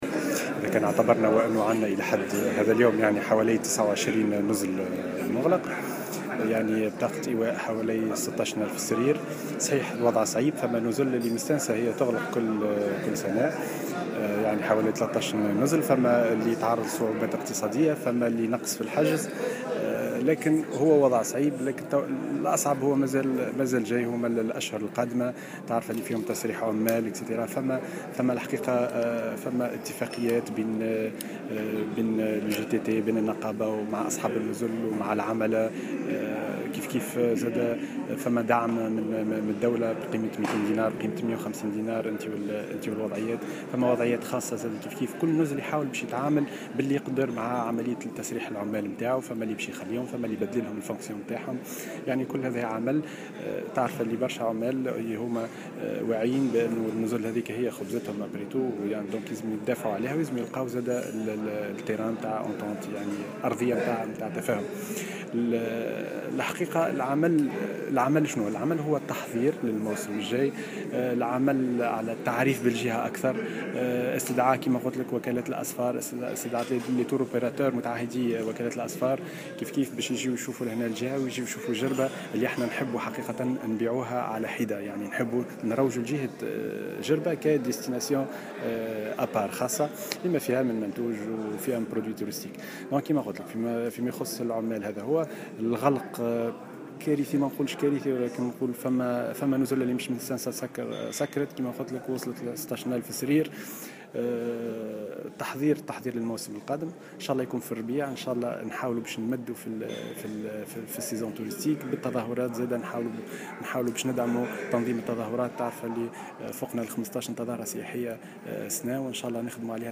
أكد المندوب الجهوي للسياحة أحمد الكلبوسي في تصريح لمراسلة الجوهرة "اف ام" بالجهة على هامش اشراف وزيرة السياحة بأحد النزل بجربة على المجلس الجهوي للسياحة بولاية مدنين أمس أن حوالي 29 نزلا أغلق أبوابه في الجهة إلى حد الان بطاقة ايواء بلغت 16 ألف سريرا مؤكدا أن الوضع صعب للغاية .